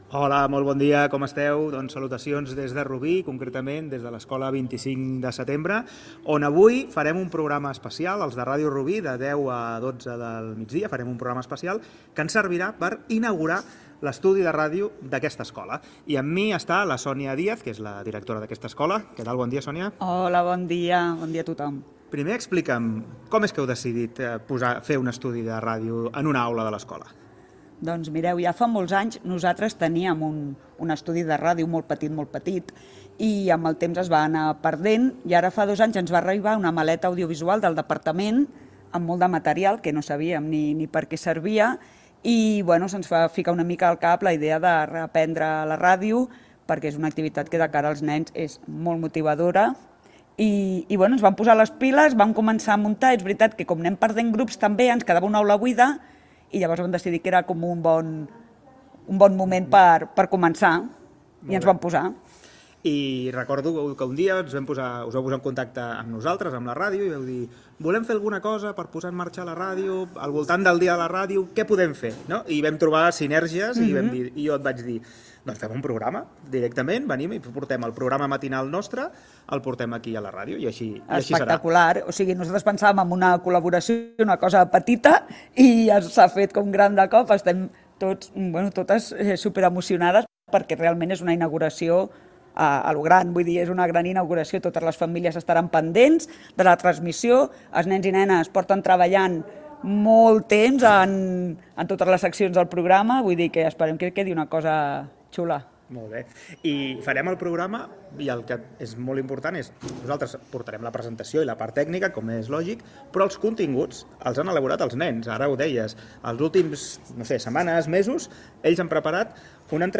Amb motiu del Dia Mundial de la Ràdio, fragment de l'emissió des de l'Escola 25 de setembrede Rubí amb motiu de la inauguració de l'estudi de ràdio en una àula.
Entreteniment